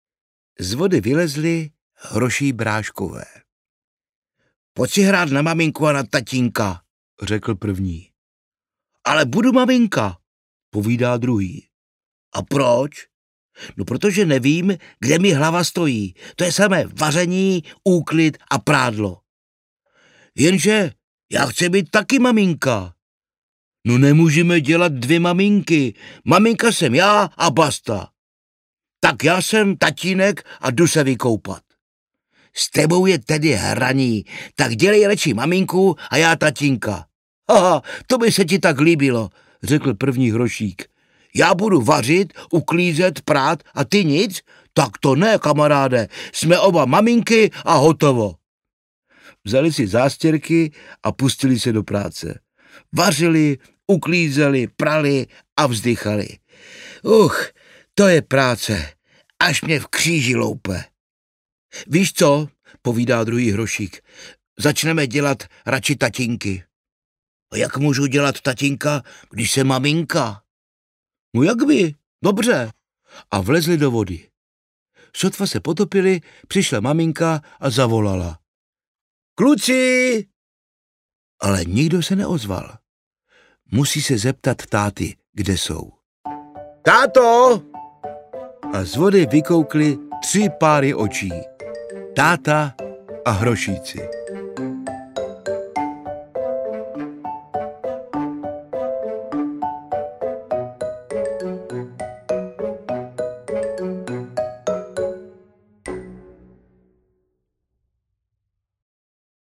Pískací kornoutek audiokniha
Ukázka z knihy
• InterpretPavel Zedníček
piskaci-kornoutek-audiokniha